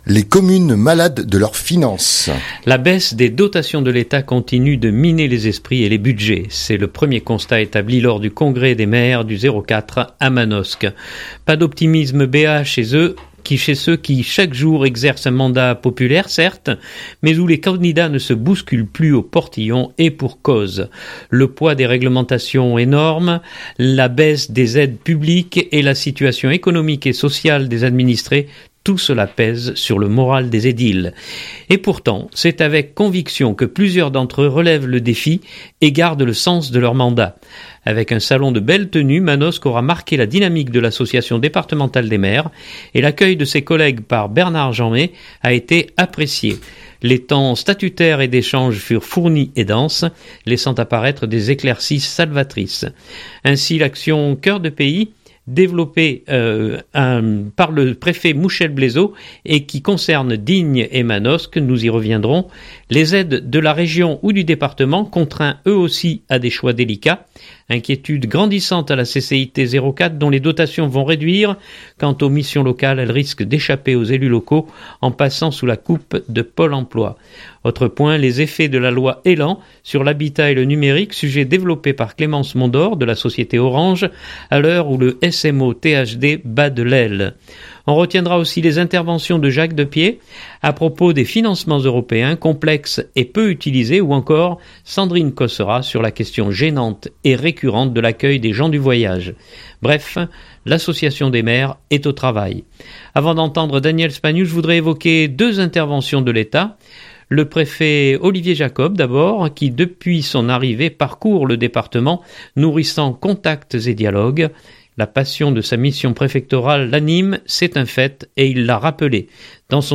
La baisse des dotations de l’Etat continue de miner les esprits et les budgets. C’est le premier constat établi lors du congrès des maires du 04 à Manosque.